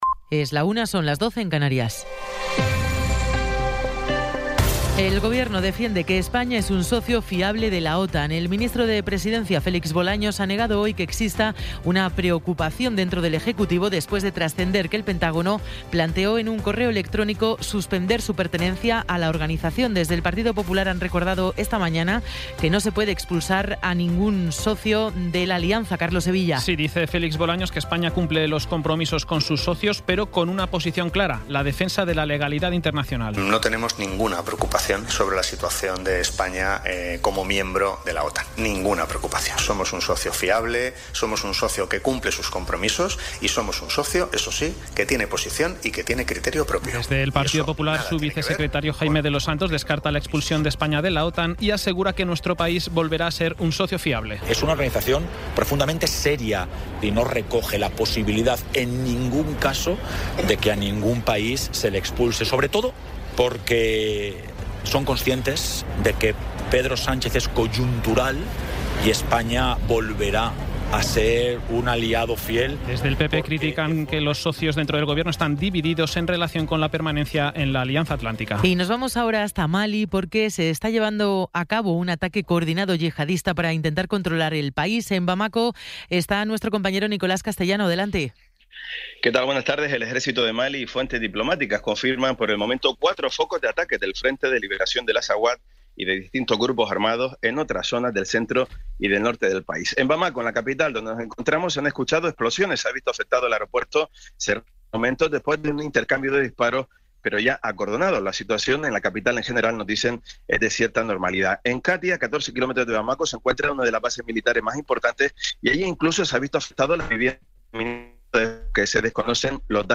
Resumen informativo con las noticias más destacadas del 25 de abril de 2026 a la una de la tarde.